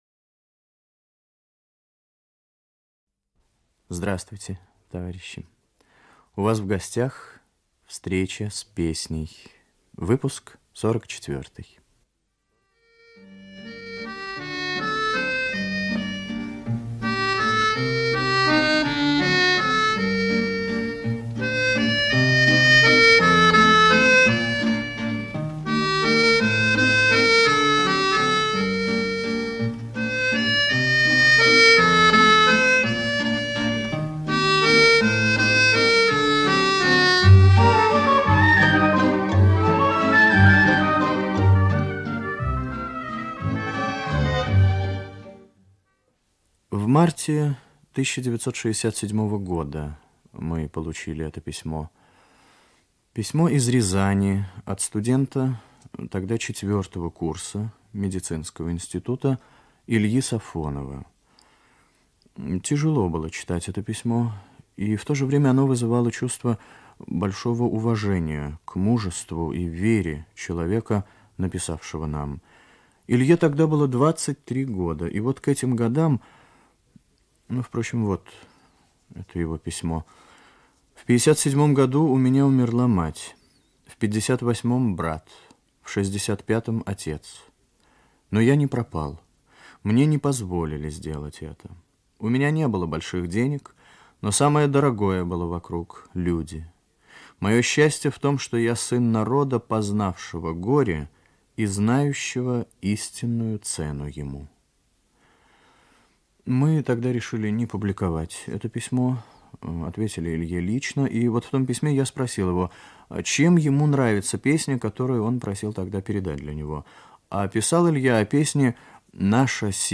Выпуск 44 (1969 год) Ведущий автор Татарский Виктор 1 .
Исполнители: Русский народный хор им. М. Пятницкого